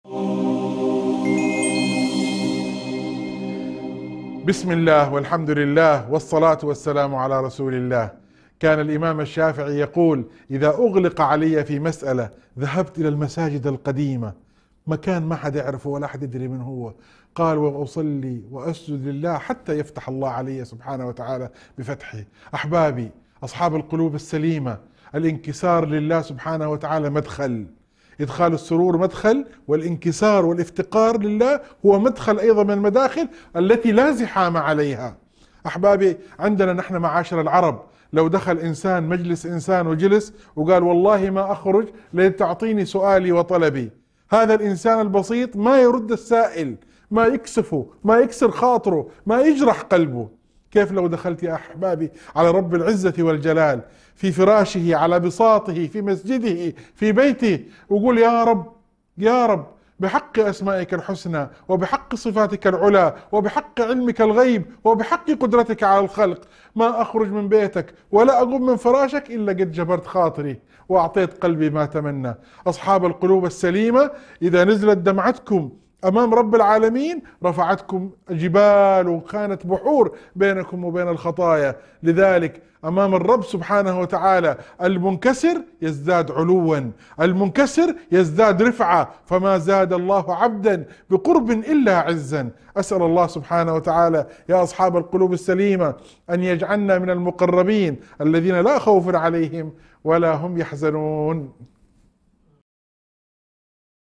موعظة مؤثرة تحث على التضرع والإنكسار بين يدي الله تعالى، مع التأكيد على أن الإلحاح في الدعاء وعدم اليأس من أبواب استجابة الدعاء. يسلط المحتوى الضوء على قيمة القلوب السليمة المنكسرة لله وكيف أن القرب منه يرفع منزلة العبد.